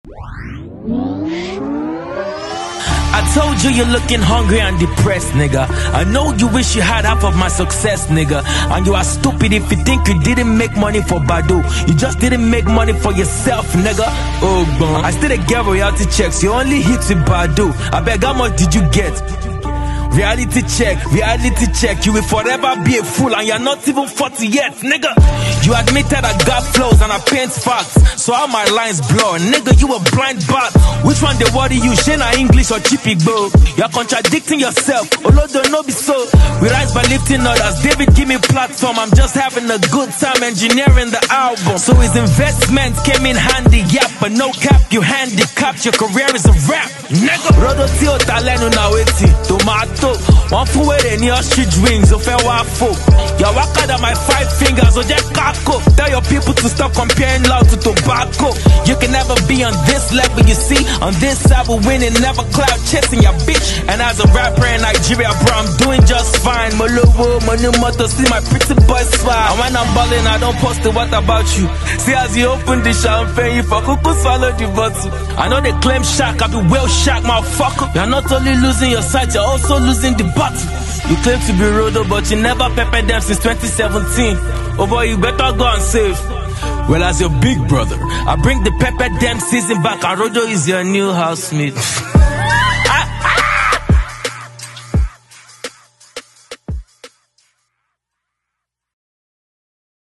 The hip Hop culture in Nigeria Is Alive!!!
diss track